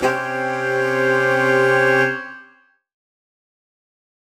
UC_HornSwellAlt_Cmin9.wav